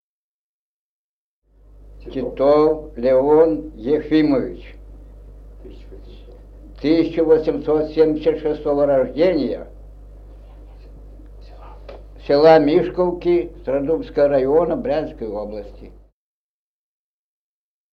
075_Представление_исполнителя_И0096-01.wav.mp3 (MP3-аудиофайл, длительность: 18 с, битрейт: 128 Кбит/с, размер файла: 281 КБ)